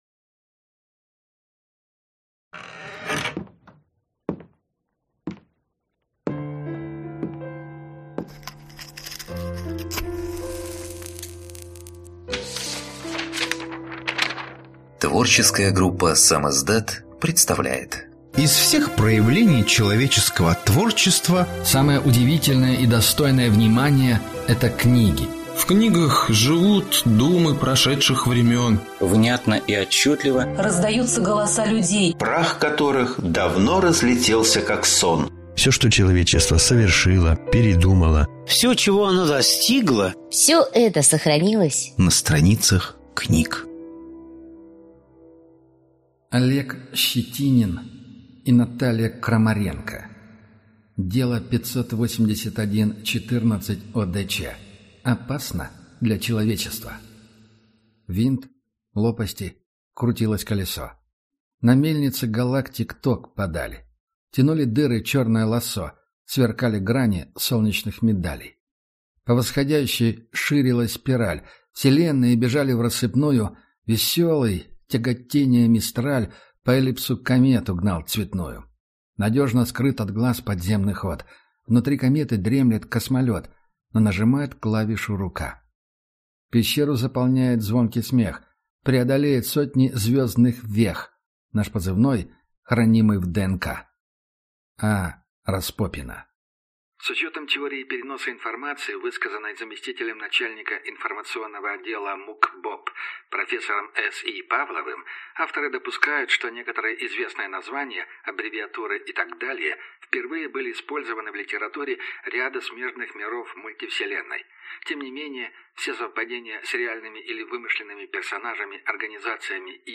Аудиокнига Дело 581-14/ОДЧ Опасно для человечества. Книга 1 | Библиотека аудиокниг